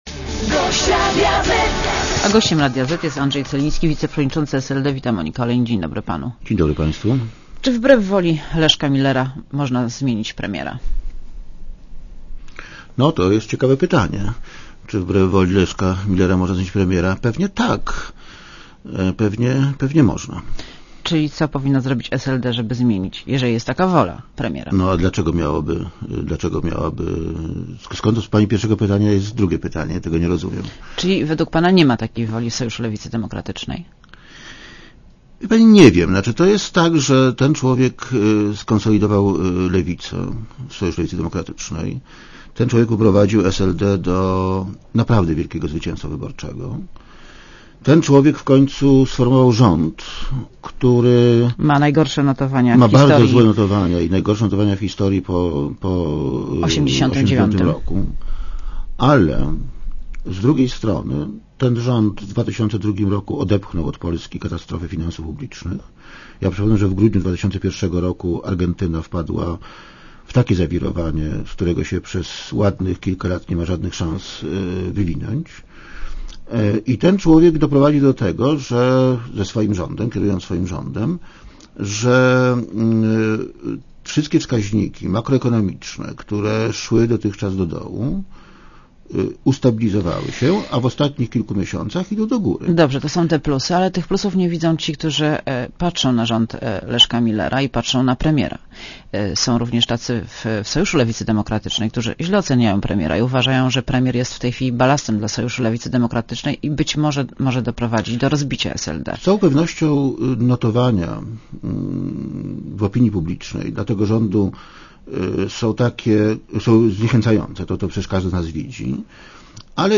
© (RadioZet) Posłuchaj wywiadu (2,64 MB) Czy wbrew woli Leszka Millera można zmienić premiera?